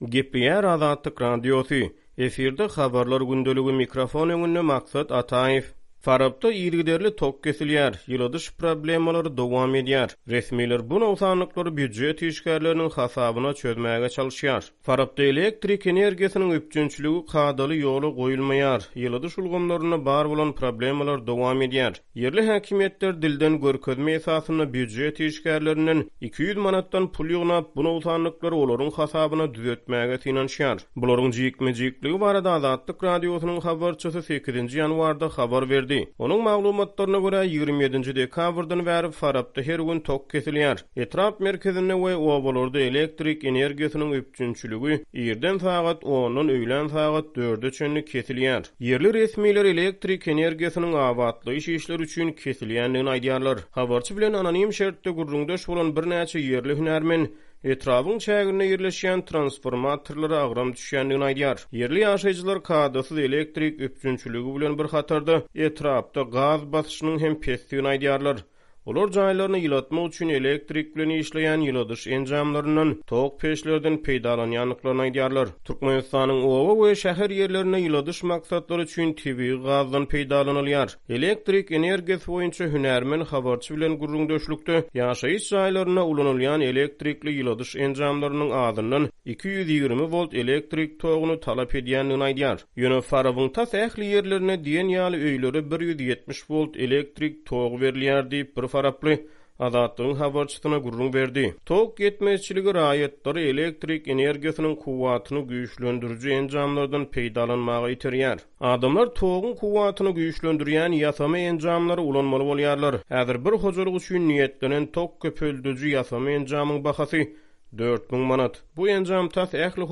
Farapda elektrik energiýasynyň üpjünçiligi kadaly ýola goýulmaýar, ýyladyş ulgamlarynda bar bolan problemalar dowam edýär. Ýerli häkimiýetler dilden görkezme esasynda, býujet işgärlerinden 200 manatdan pul ýygnap, bu nogsanlyklary olaryň hasabyna düzetmäge synanyşýar. Bularyň jikme-jikligi barada Azatlyk Radiosynyň habarçysy 8-nji ýanwarda habar berdi.